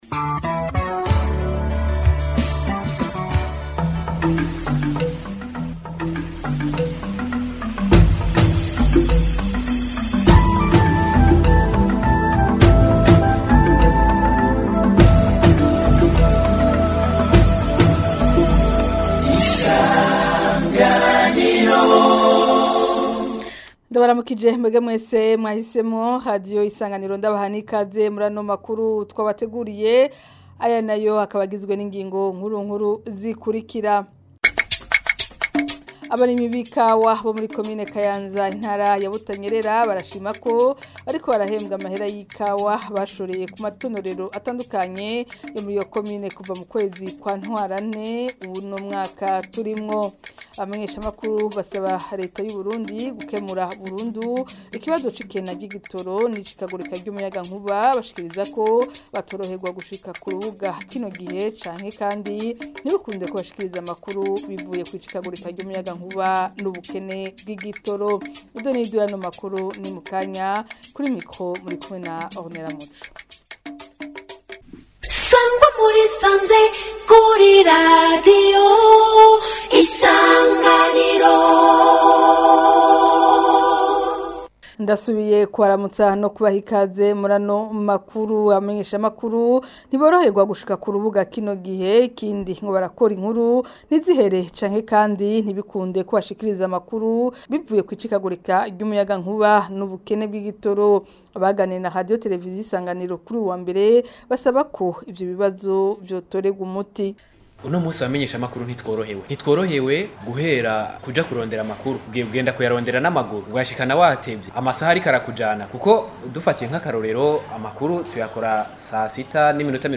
Amakuru yo ku wa 29 Nyakanga 2025